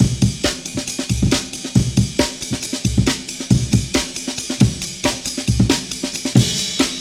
The tempo is 137 BPM.
48 kHz, warped Amen Break, "Fixed" version where the first drum is replaced with a clean one.